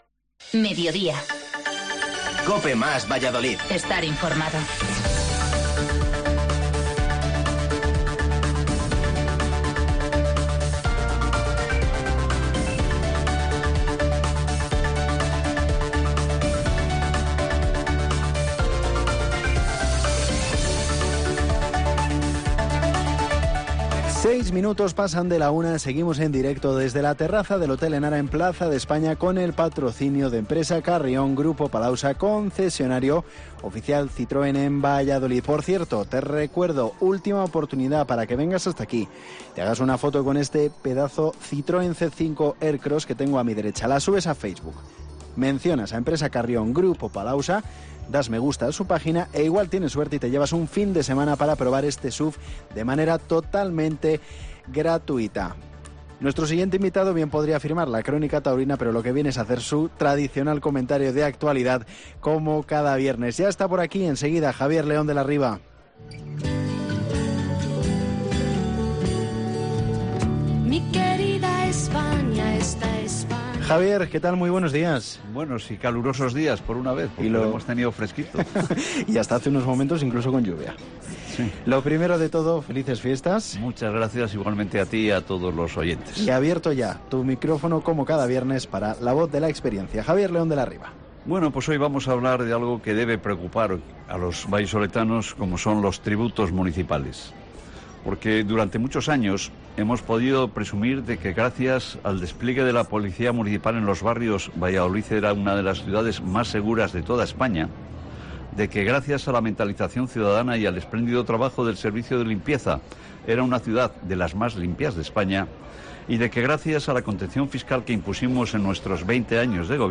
AUDIO: Desde la terraza del Hotel Enara con Juanjo Artero la obra un Marido Ideal, y terminamos con Una tienda en Paris del Teatro Cervantes